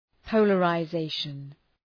Προφορά
{,pəʋlərə’zeıʃən}